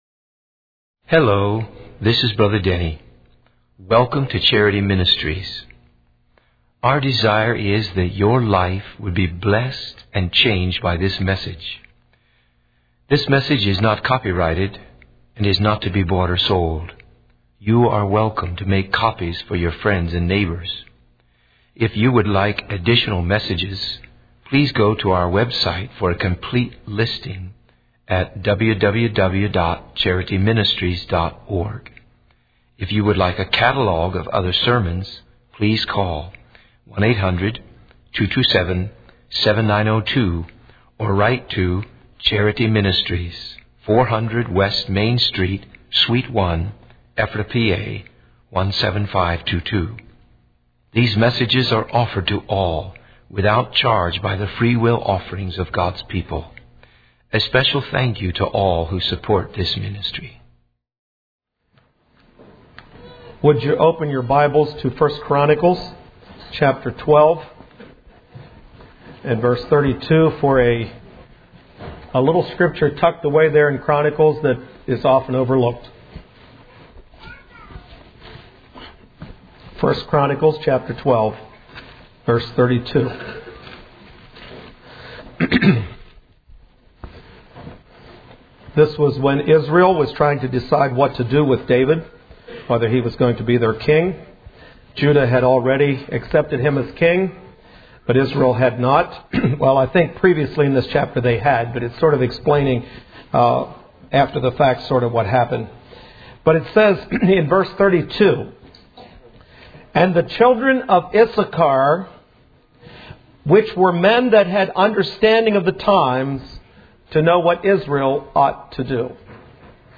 In this sermon, the preacher discusses the structure of a song and how it relates to worship. He explains the concept of the sonata form, which includes an exposition, development, and recapitulation. The preacher emphasizes the importance of hymns that come from the hearts of martyrs and those who have suffered for their faith.